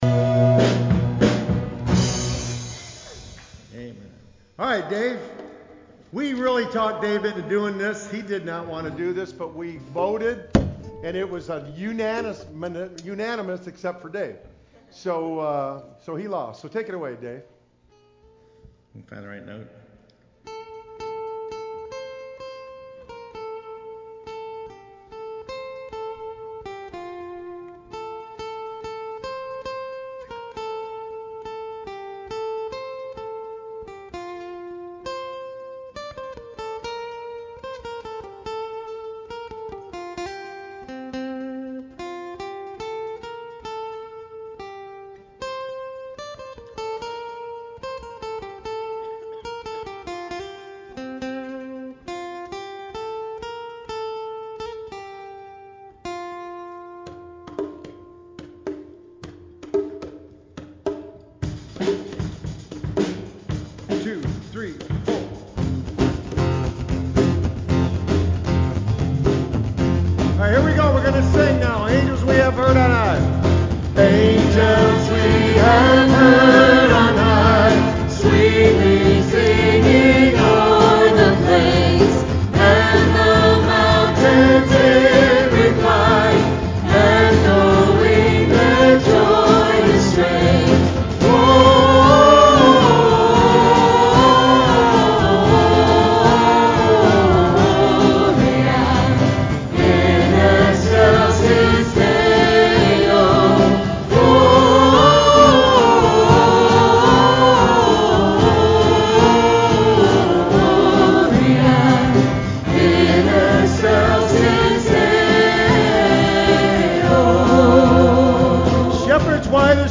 12-18-16 PM Candlelight Service